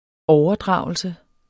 Udtale [ ˈɒwʌˌdʁɑˀwəlsə ]